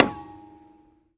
管子 " 管子被石头击中
描述：用石头打塑料建筑管，共鸣和长尾。
Tag: 命中 长尾 谐振